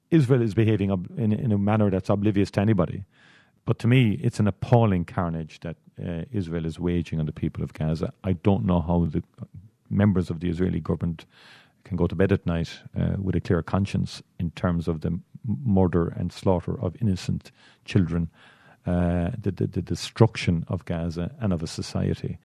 Taoiseach Michael Martin says what’s happening in Gaza is ‘beyond any moral compass’: